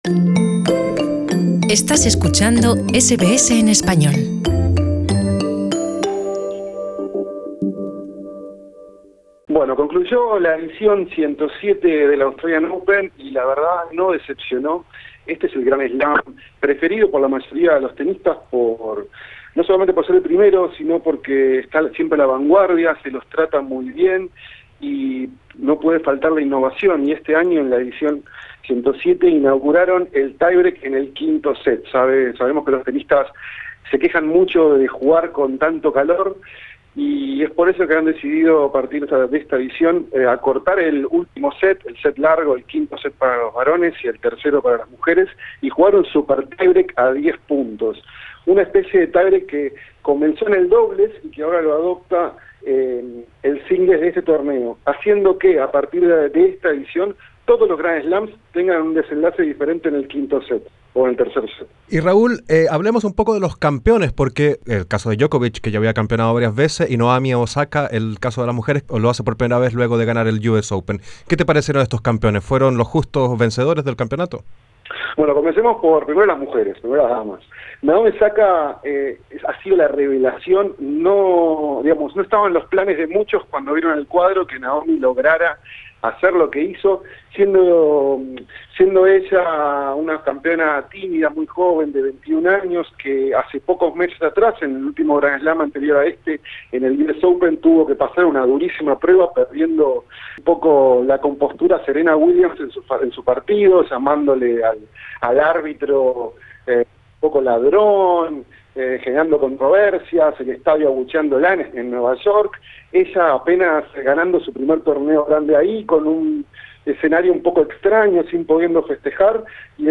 En Radio SBS hablamos con el periodista deportivo experto en tenis